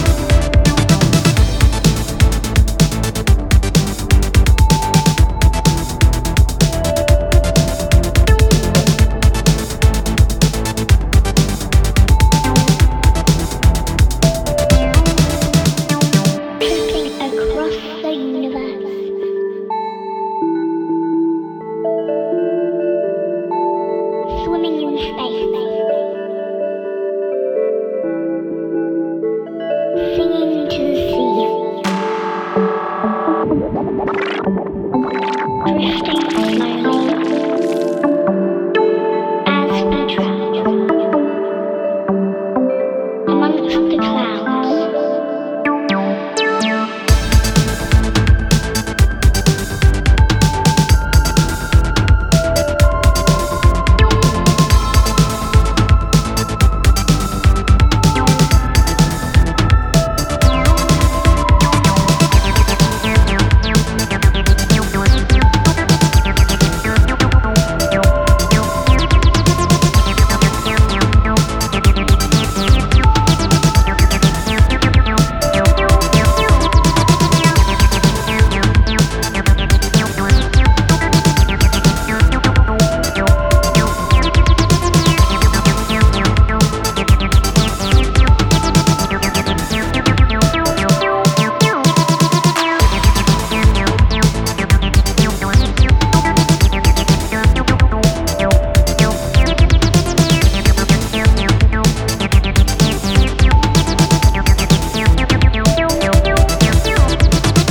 electronic music producer
hard hitting dance floor bangers
deep and groove lead hypnotic classics